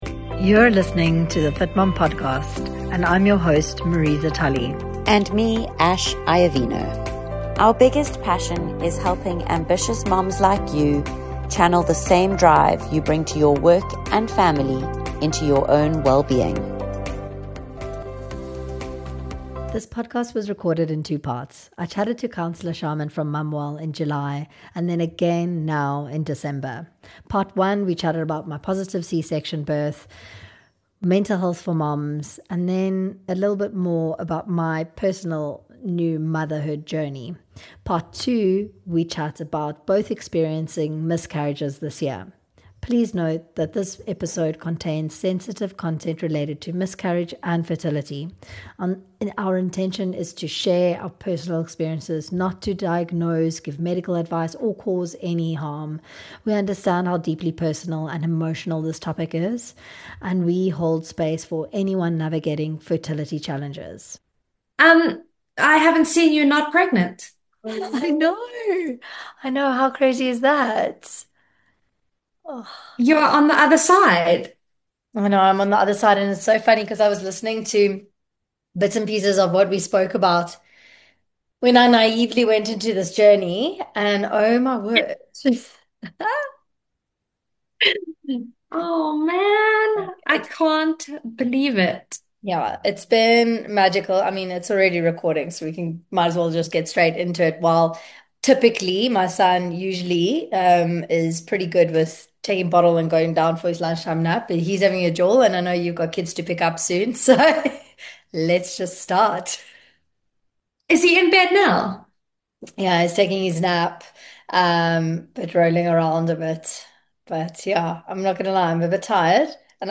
Part 1 was recorded in July, where we talked about my positive C-section experience, early motherhood, and the emotional shifts that come with becoming a mom for the first time. Five months later, we sat down again to talk about something we both navigated this year - miscarriage, grief, and the silent emotional load so many women carry.